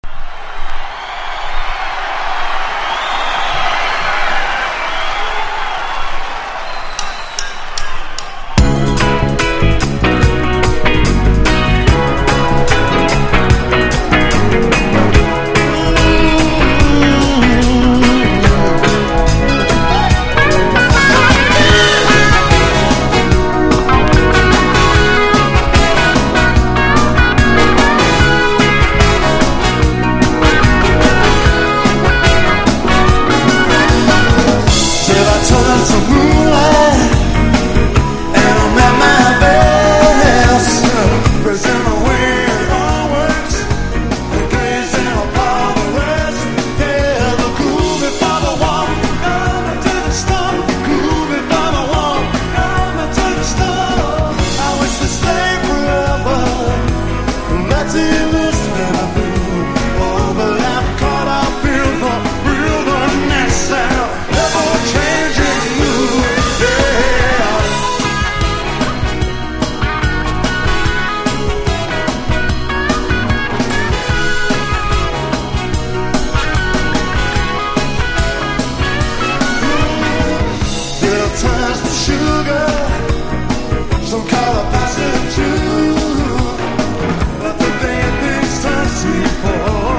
1. NEW WAVE >
熱狂的なライブになってます。
NEO ACOUSTIC / GUITAR POP